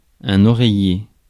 Ääntäminen
IPA : /ˈpɪ.ləʊ/